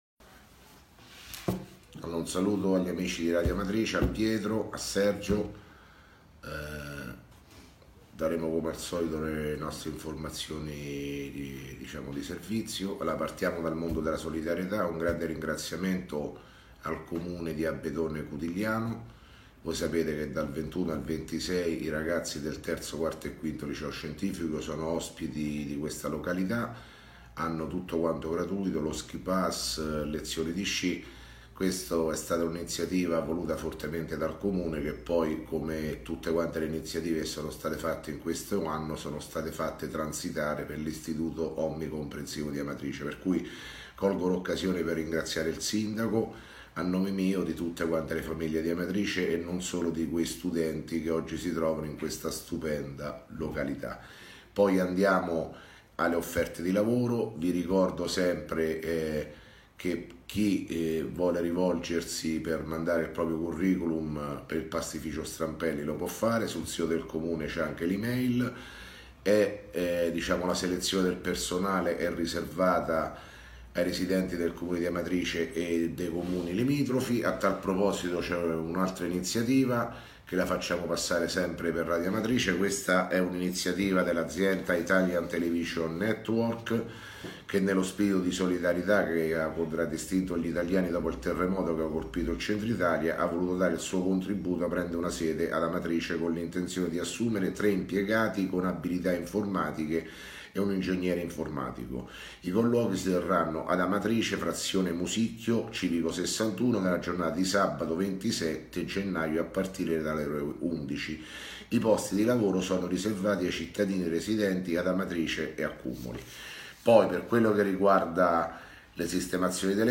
Di seguito il messaggio audio del Sindaco Sergio Pirozzi del 25 gennaio 2018: